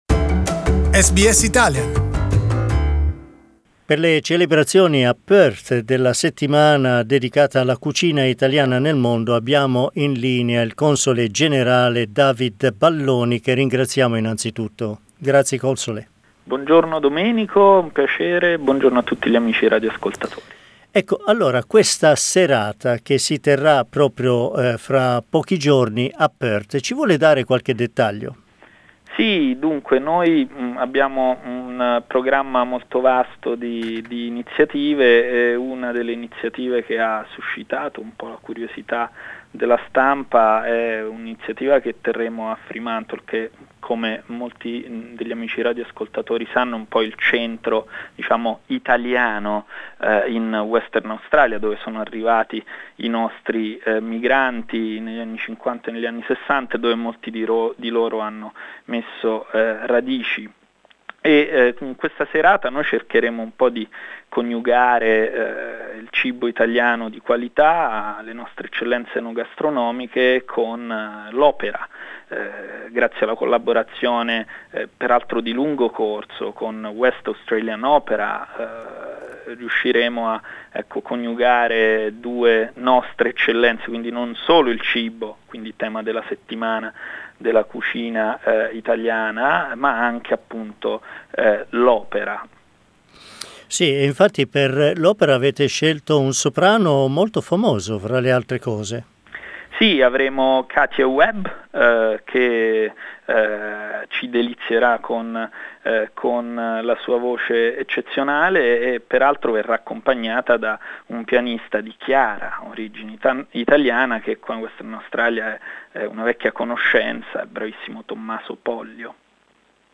Anche il Western Australia celebra la terza edizione della Settimana della cucina italiana nel mondo. Ne parliamo con David Balloni, Console generale d'Italia per il Western Australia.